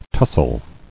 [tus·sle]